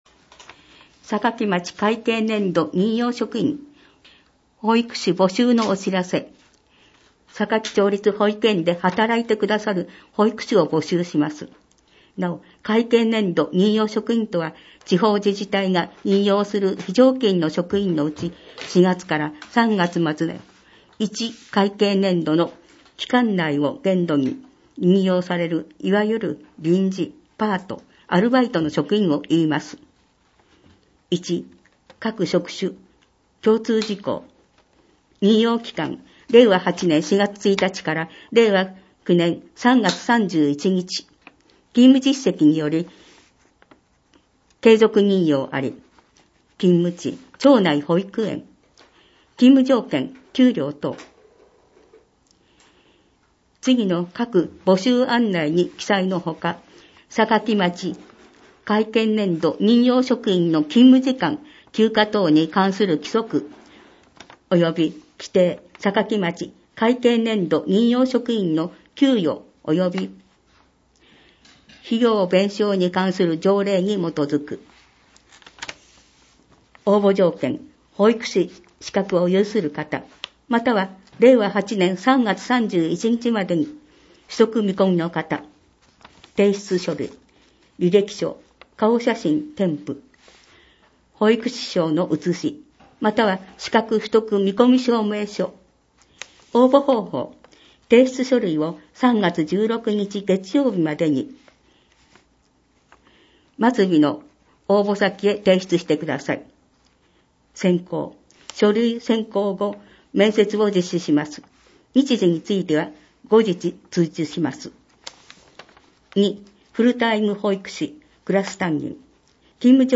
また、音訳ボランティアサークルおとわの会のみなさんによる広報の音訳版のダウンロードもご利用ください。